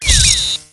misc.error.ogg